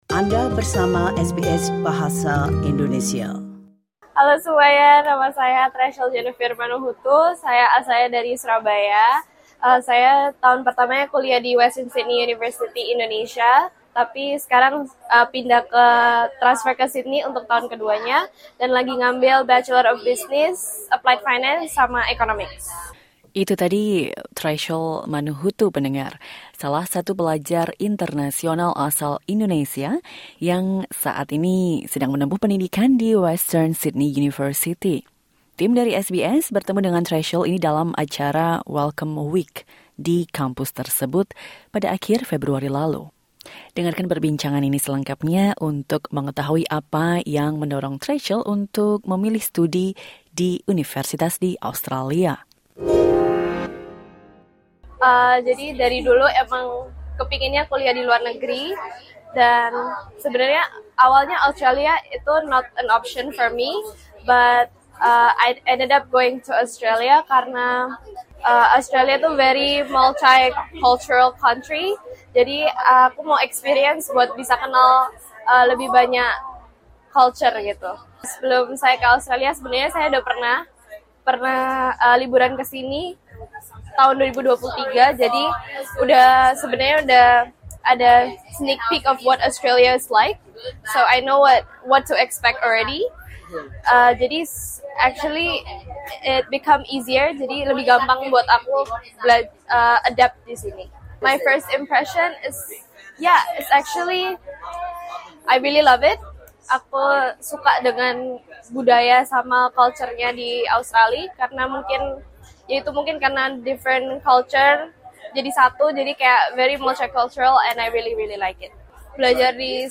Note: The information shared in this interview is of general nature and may not suit your personal situation.